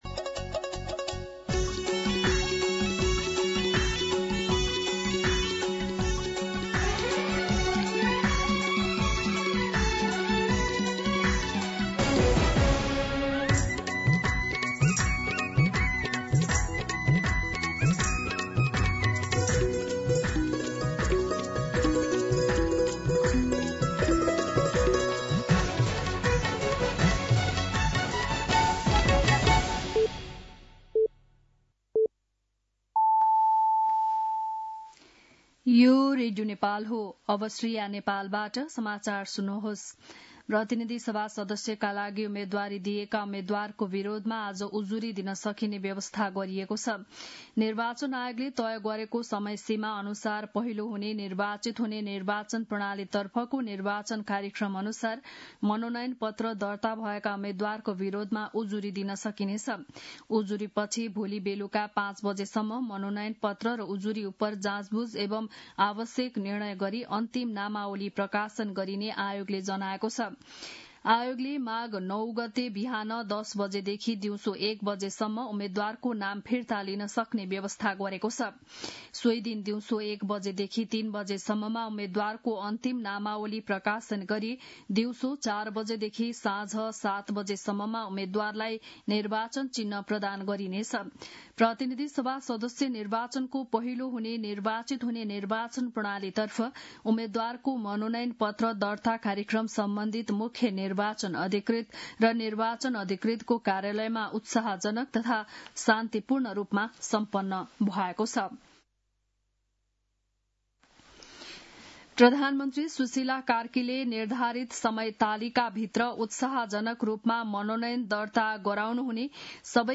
बिहान ११ बजेको नेपाली समाचार : ७ माघ , २०८२
11-am-Nepali-News-7.mp3